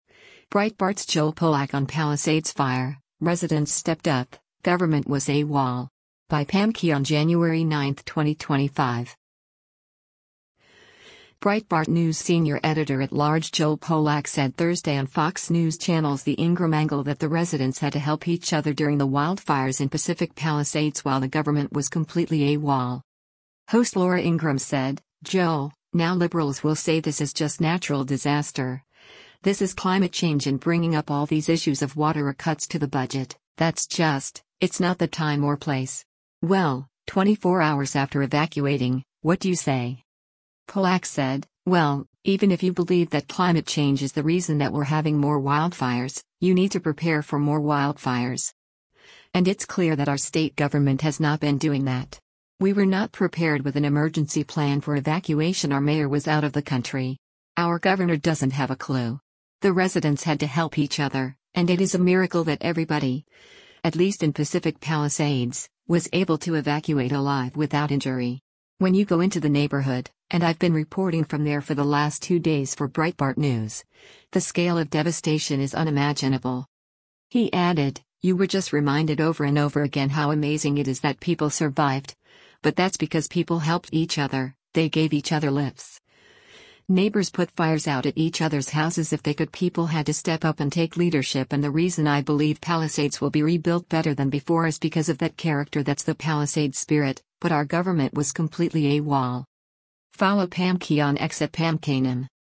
Breitbart News senior editor-at-large Joel Pollak said Thursday on Fox News Channel’s “The Ingraham Angle” that the residents had to help each other during the wildfires in Pacific Palisades while the government “was completely AWOL.”